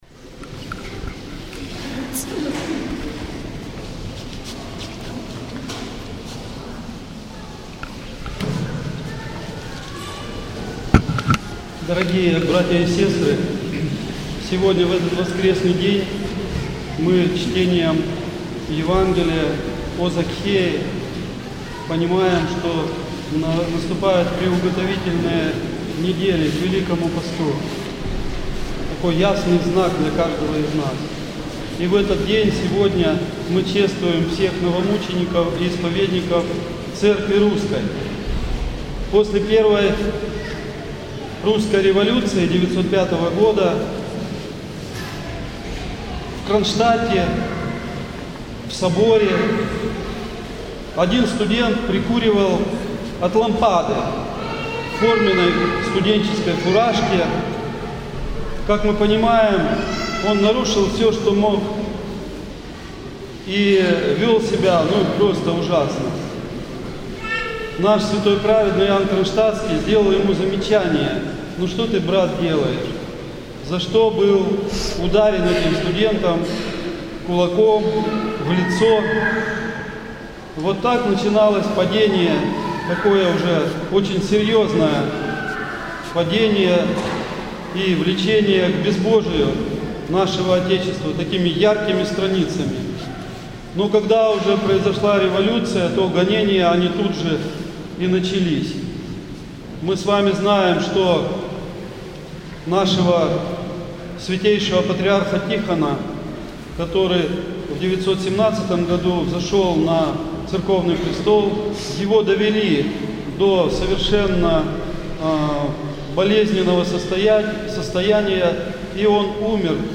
поздняя Литургия 10 февраля 2019г.